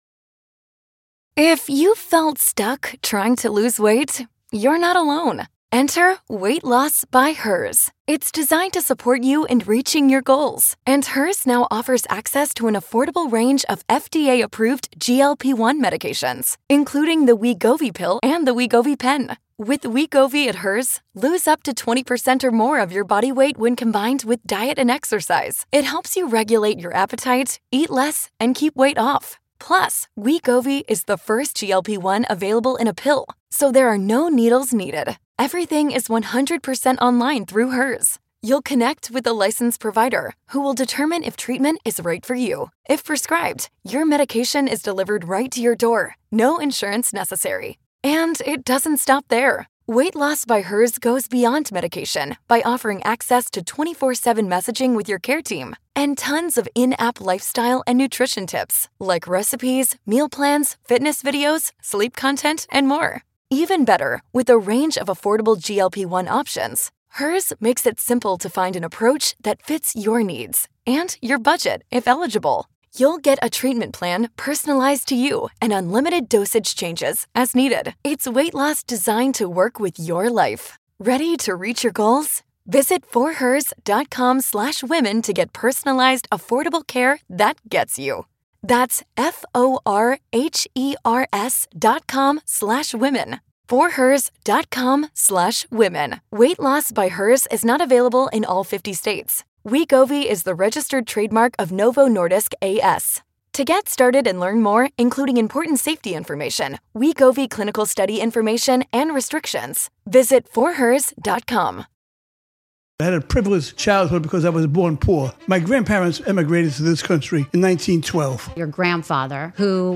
A few months ago, I had the privilege of traveling to Miami to interview him in person at his home, surrounded by his family. We shared a heartfelt, candid conversation about his life, his values, and the lessons he wanted to leave behind.